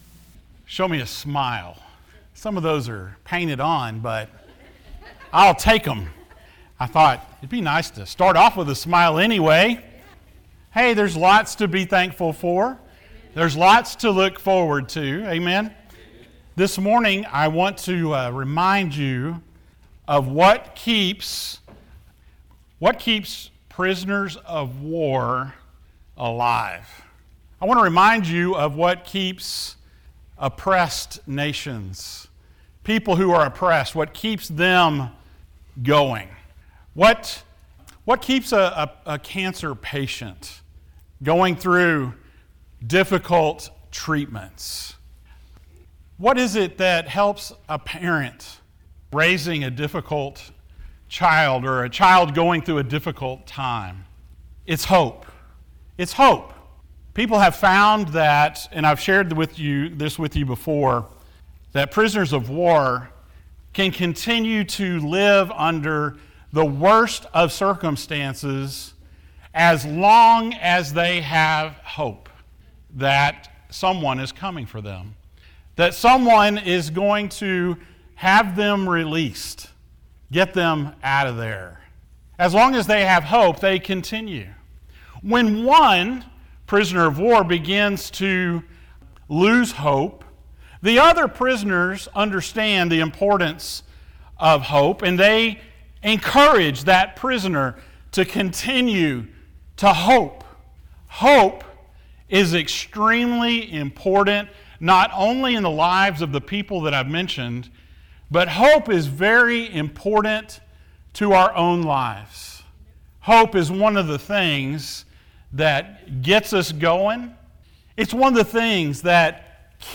Christmas 2025 Passage: Isaiah 9:6-7 Service Type: Sunday Morning Thank you for joining us.